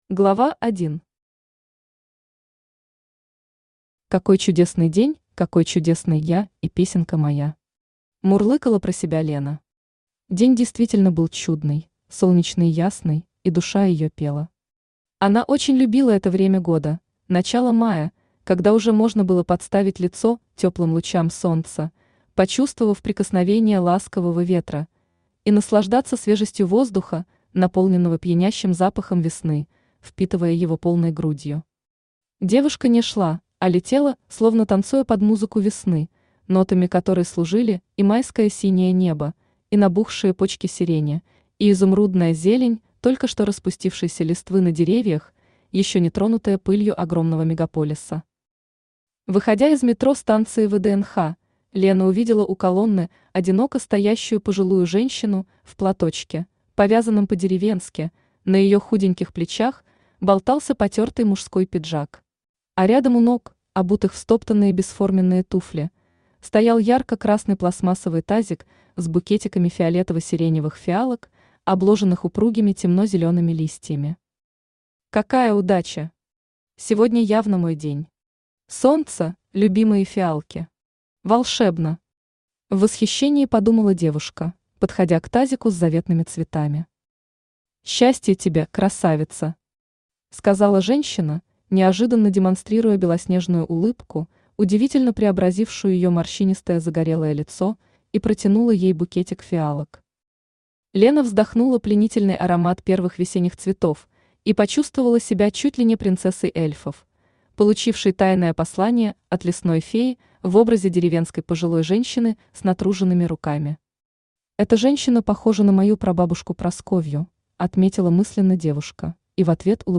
Аудиокнига Повороты судьбы. Сентиментальный роман | Библиотека аудиокниг
Сентиментальный роман Автор Валентина Павловна Светлакова Читает аудиокнигу Авточтец ЛитРес.